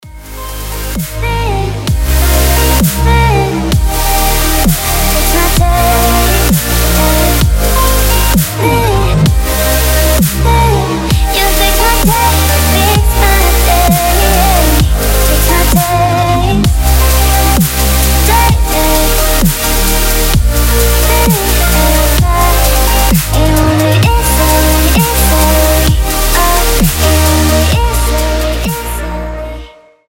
громкие
мелодичные
Electronic
Стиль: melodic dubstep